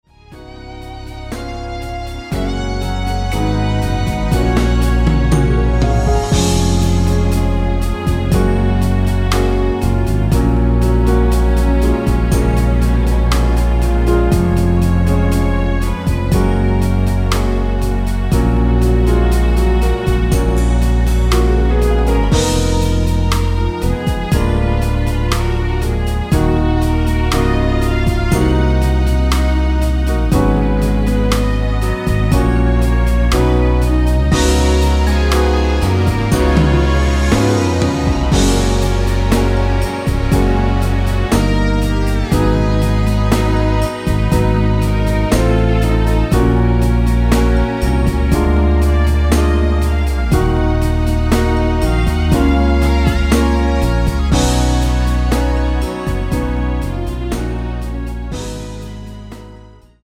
내린 MR 입니다.
F#
◈ 곡명 옆 (-1)은 반음 내림, (+1)은 반음 올림 입니다.
앞부분30초, 뒷부분30초씩 편집해서 올려 드리고 있습니다.
중간에 음이 끈어지고 다시 나오는 이유는